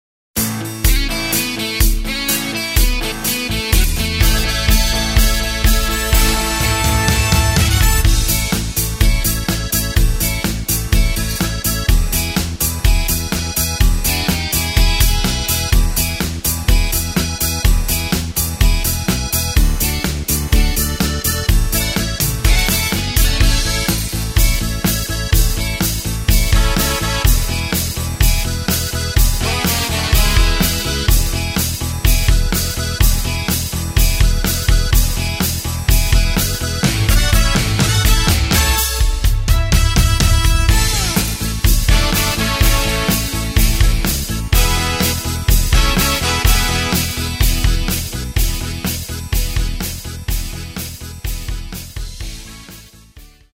Takt:          4/4
Tempo:         125.00
Tonart:            A
Playback mp3 Demo